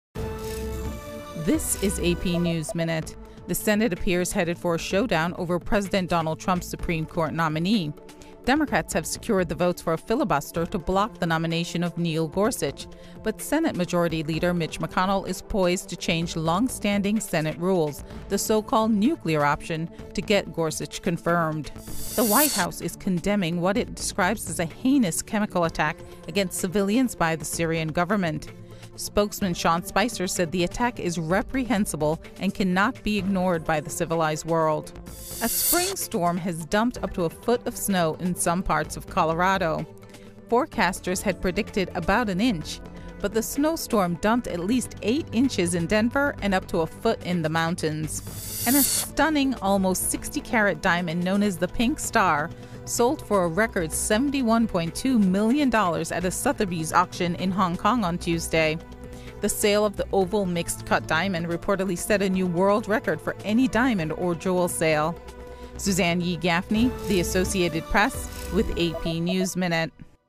美语听力练习素材:白宫谴责叙利亚化学品袭击